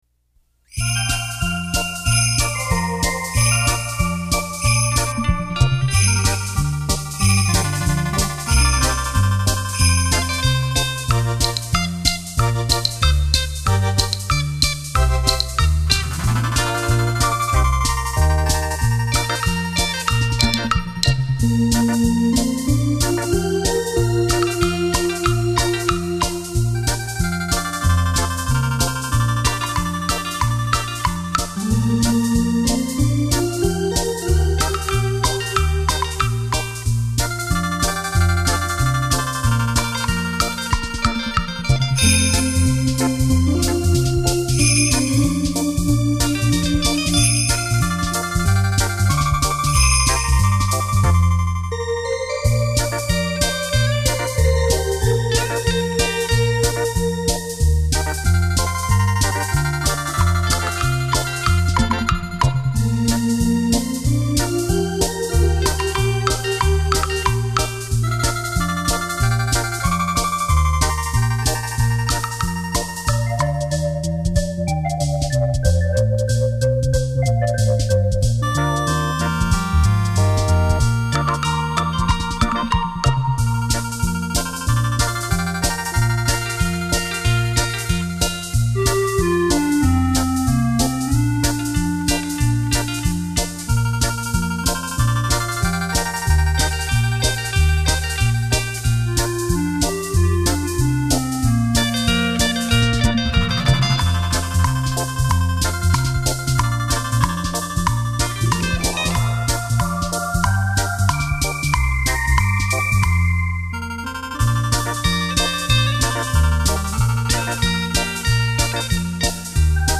曲目选自闽南民谣，节奏明快，旋律优美。